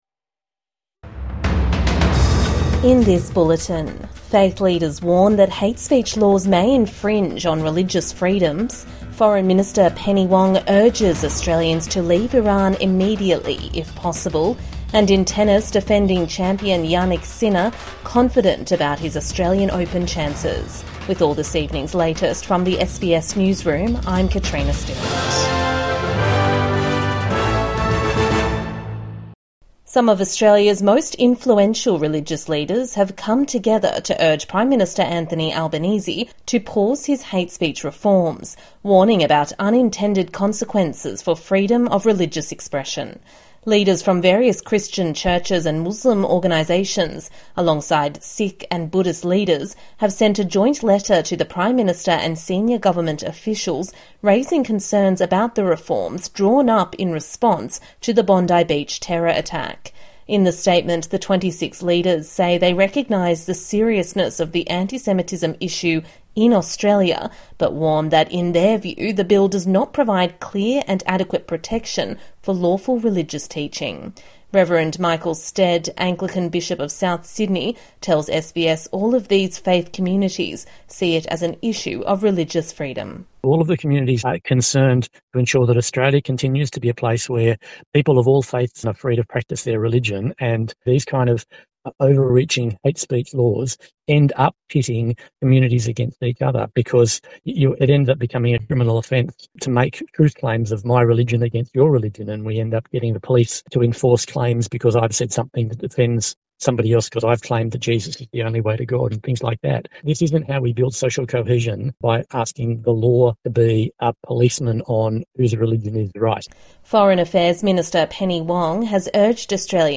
Faith leaders call for pause in hate speech laws | Evening News Bulletin 16 January 2026